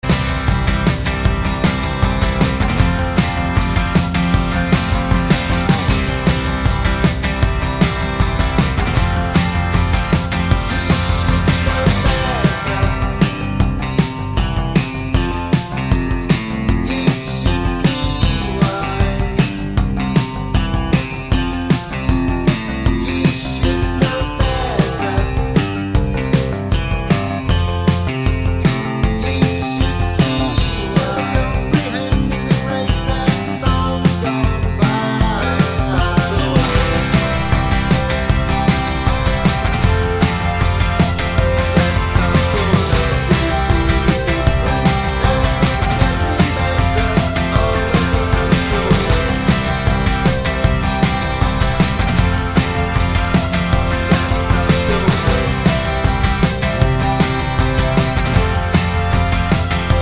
no Backing Vocals Rock 3:47 Buy £1.50